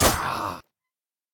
sceleton_hit2.ogg